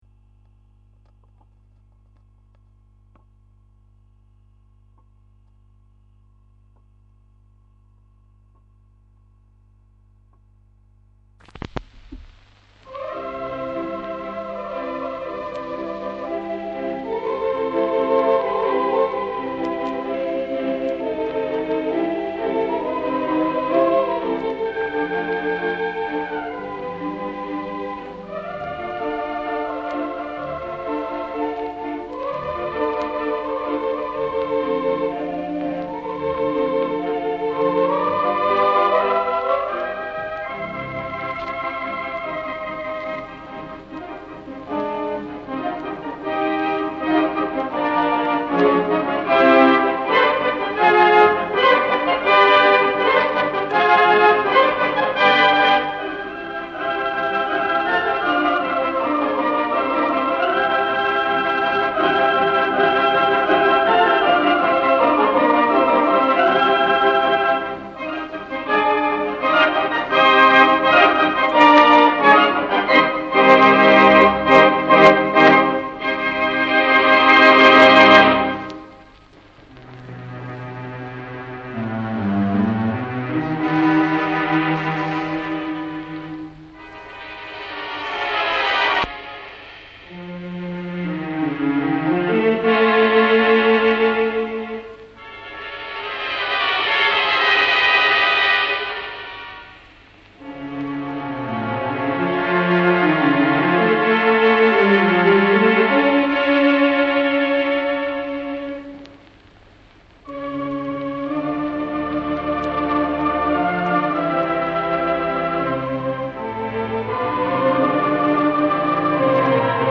organ
Regal Cinema, Marble Arch